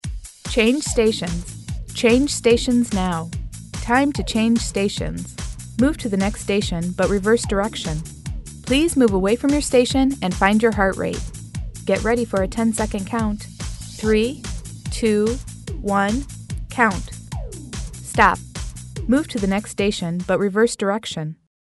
All our Cue CDs are studio recorded and work great on all 30 minute style fitness circuits.
Double Take Cue: A basic Male or Female prompt to change stations 3 times and on the 4th change station prompt it adds to reverse direction.
The "previews" have music background for reference.
FEMALE CUES
CueCD-DblTk-Female.mp3